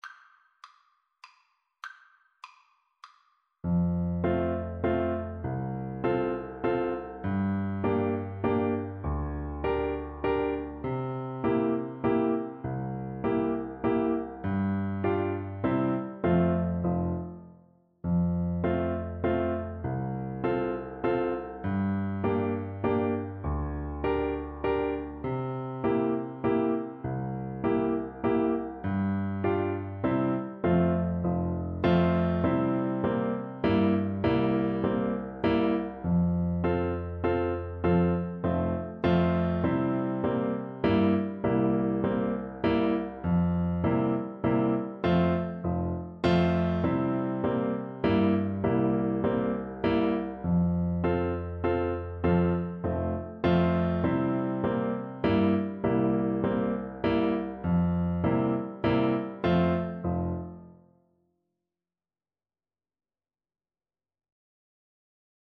Classical Schubert, Franz Waltz in A Clarinet version
Play (or use space bar on your keyboard) Pause Music Playalong - Piano Accompaniment Playalong Band Accompaniment not yet available transpose reset tempo print settings full screen
Clarinet
F major (Sounding Pitch) G major (Clarinet in Bb) (View more F major Music for Clarinet )
3/4 (View more 3/4 Music)
Classical (View more Classical Clarinet Music)